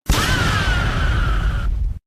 Fahhh Pump Sound